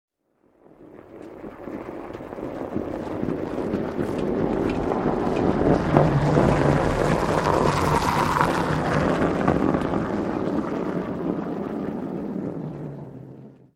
Звуки машины, снега
Звук машины медленно едущей по снегу скрип колес